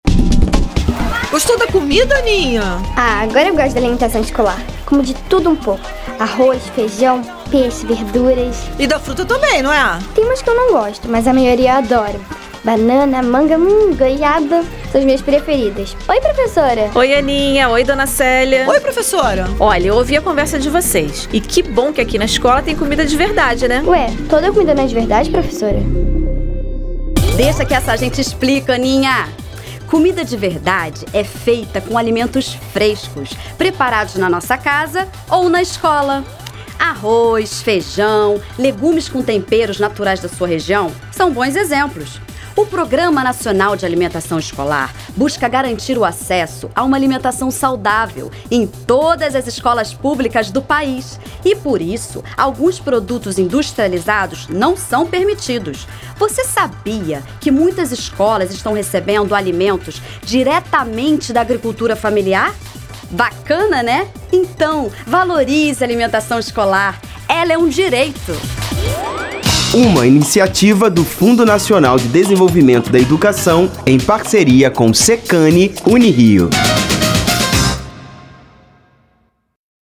Spot de rádio - Promoção da alimentação saudável nas escolas